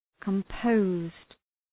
Προφορά
{kəm’pəʋzd}